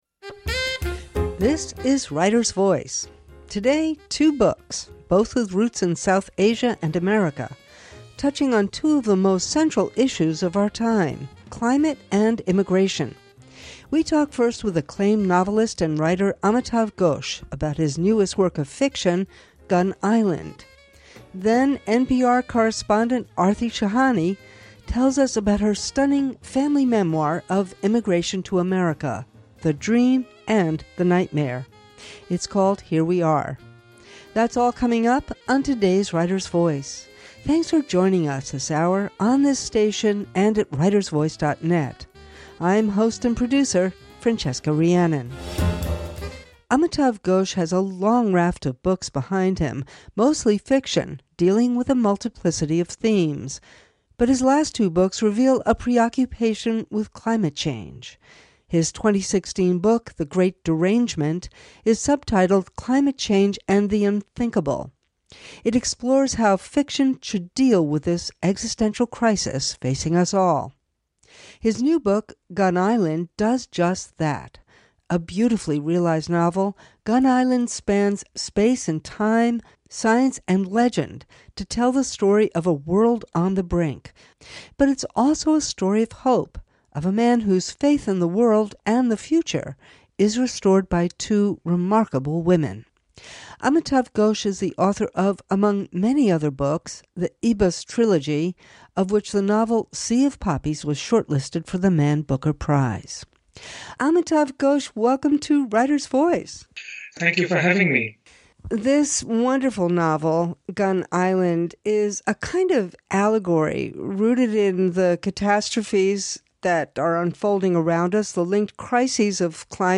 In this episode, we talk with celebrated Haitian American writer Edwidge Danticat, author of Brother, I’m Dying and her latest essay collection, We’re Alone.
We also play a portion of our 2010 interview with Tracy Kidder about his book Mountains Beyond Mountains .